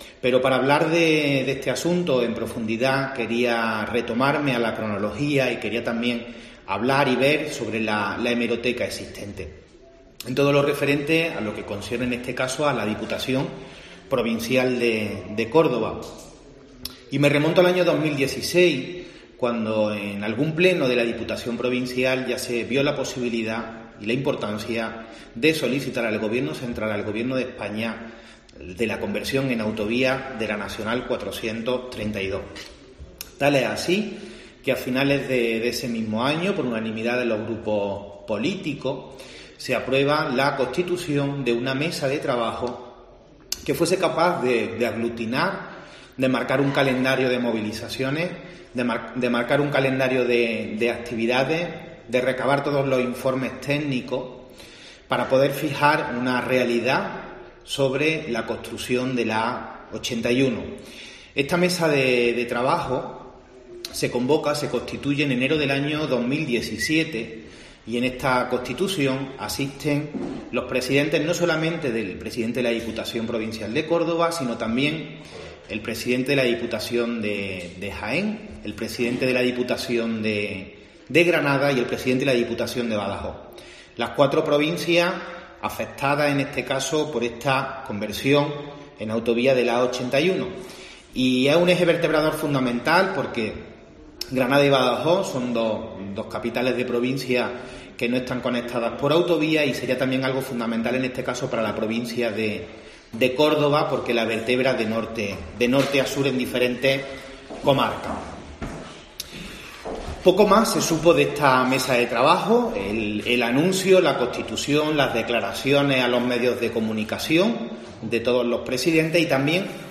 A este respecto y en rueda de prensa, Romero ha recordado que la conversión en autovía de la N-432 "es una demanda histórica para la provincia de Córdoba", pues es "una vía de comunicación esencial para el desarrollo económico de la provincia", que la vertebra de Norte a Aur, uniéndola a las provincias de Granada y Badajoz, además se "ser de vital importancia para seguridad vial de las personas que transitan por ella".